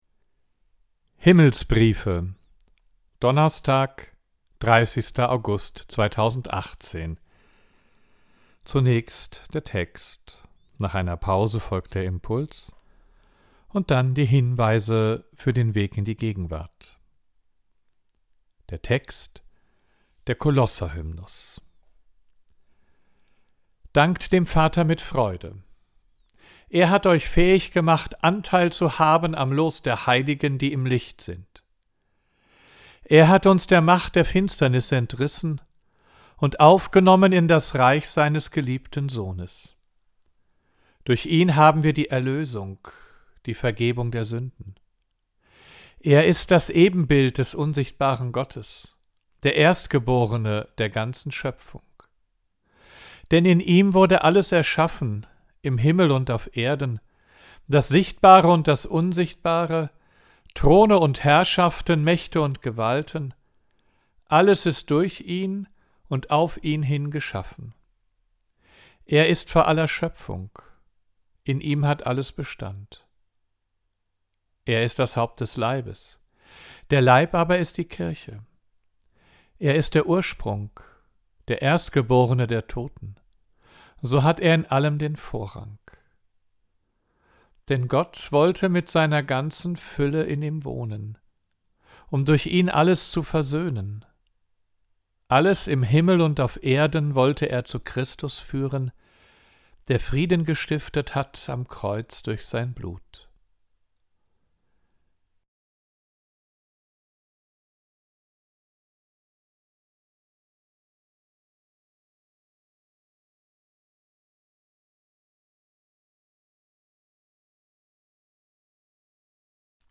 Audio: Text und Impuls